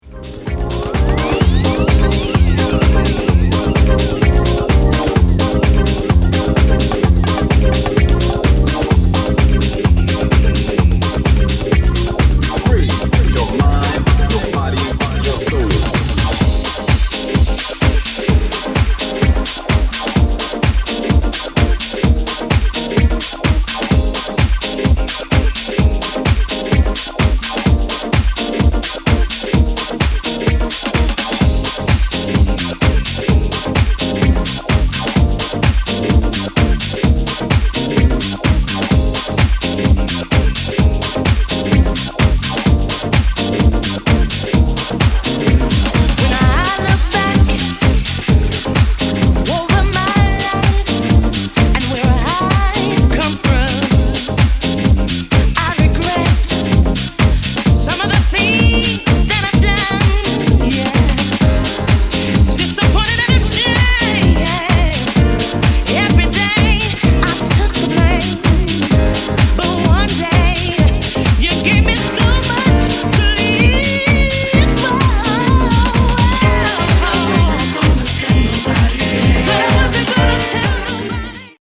Here’s an example of another track fitting together well with the first one so that even a long transition sounds easy and natural.
harmonic_mixing-harmonic.mp3